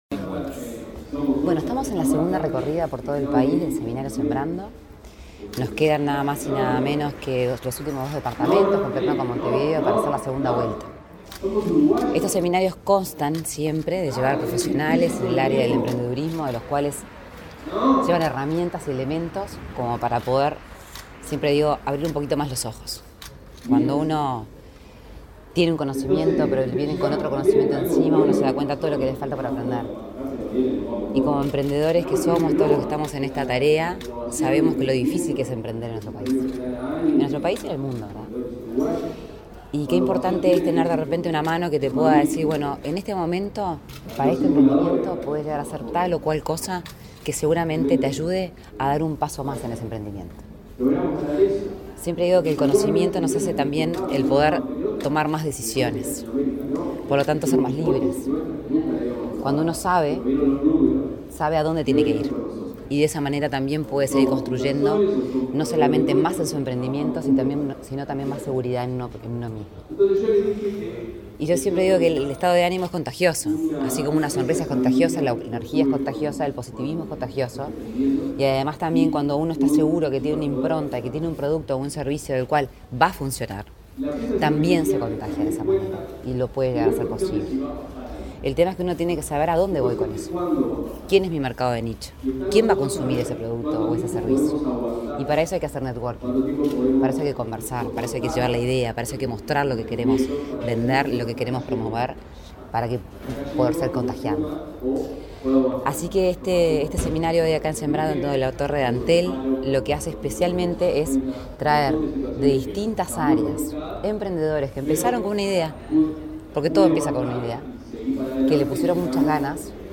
Declaraciones de la impulsora del programa Sembrando, Lorena Ponce de León 05/09/2023 Compartir Facebook X Copiar enlace WhatsApp LinkedIn Este martes 5, la impulsora de Sembrando, Lorena Ponce de León, dialogó con la prensa luego de participar en la apertura de un encuentro de emprendedores, organizado por Antel y ese programa de apoyo a pequeños empresarios.